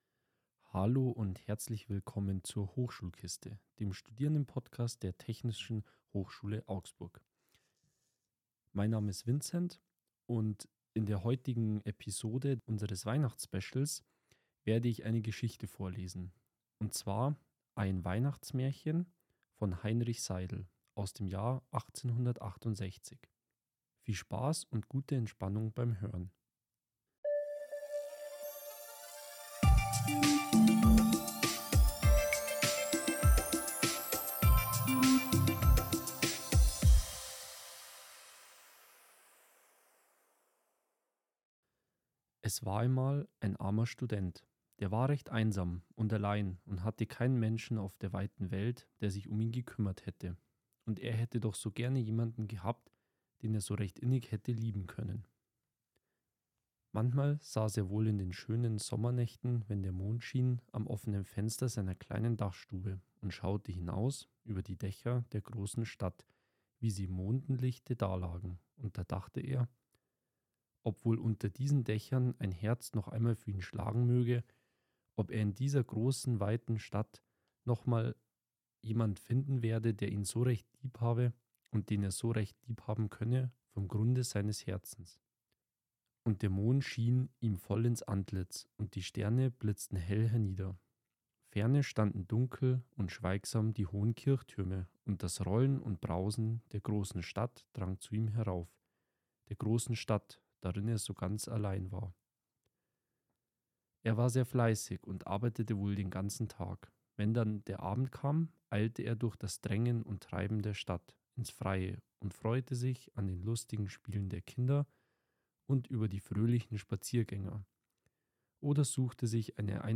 season_0-ein-weihnachtsmaerchen.mp3